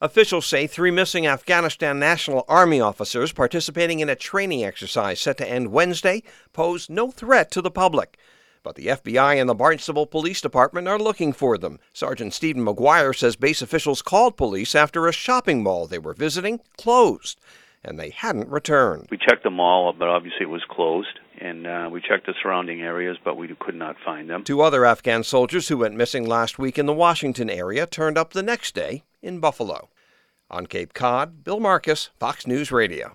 FOX NEWS RADIO’S